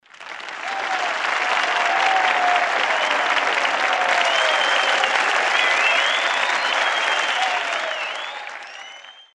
applause-and-cheers.mp3